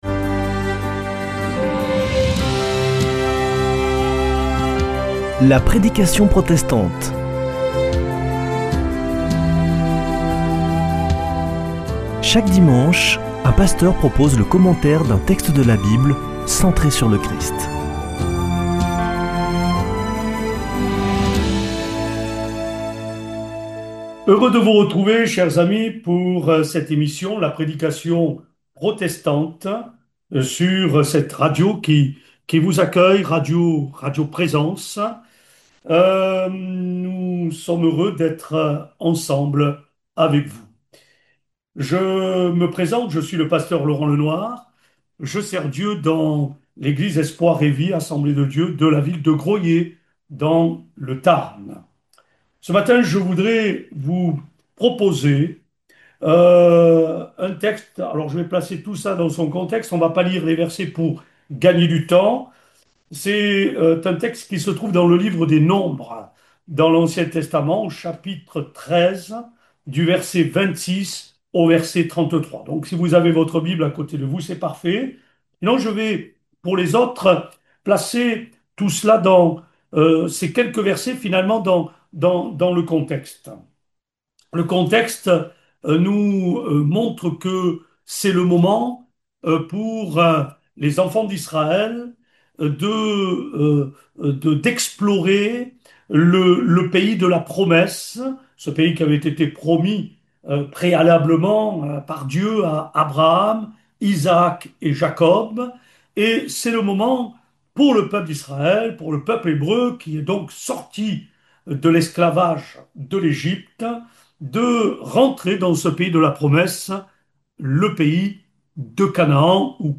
Accueil \ Emissions \ Foi \ Formation \ La prédication protestante \ Chemin de la foi ou de l’incrédulité ?